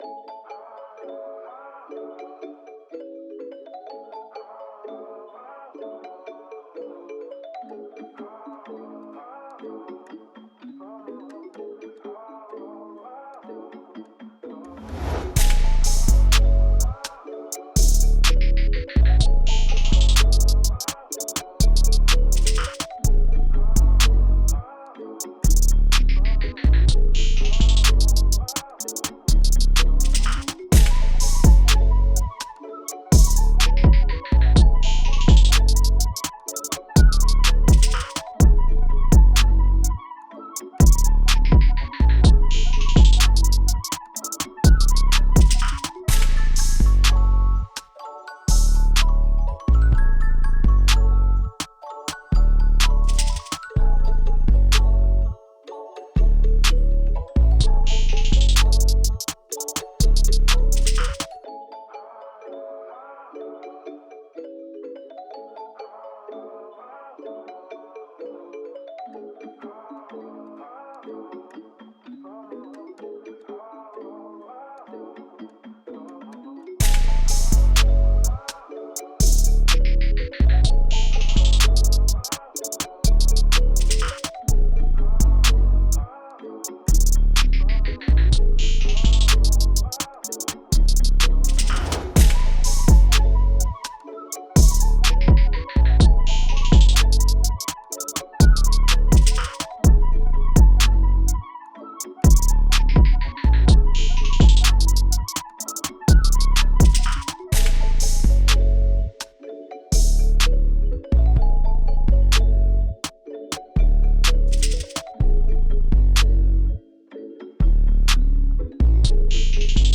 Hip Hop Instrumentals